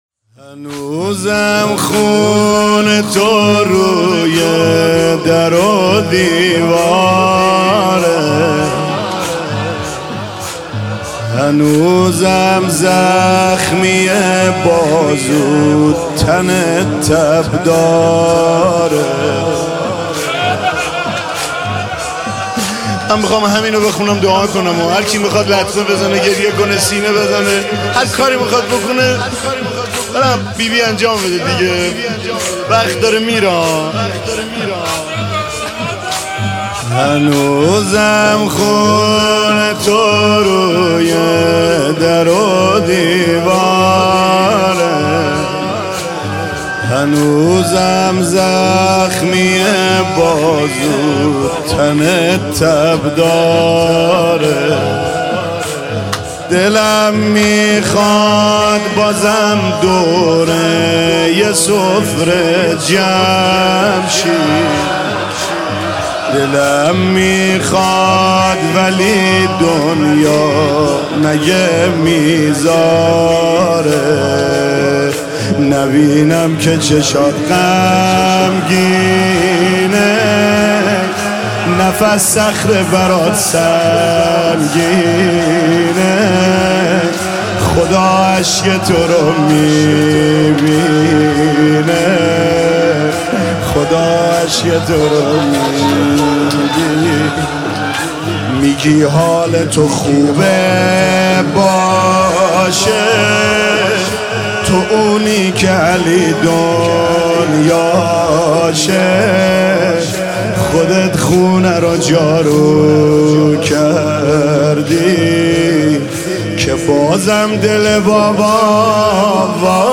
مداحی زمینه
نوحه